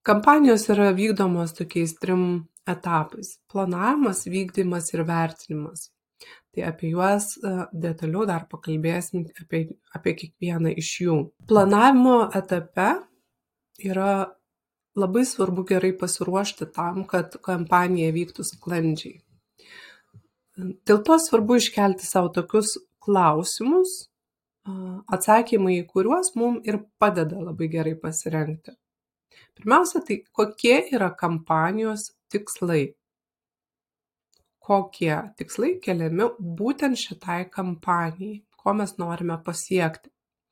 Komunikacijos-kamapnijos-seminaro-istrauka.mp3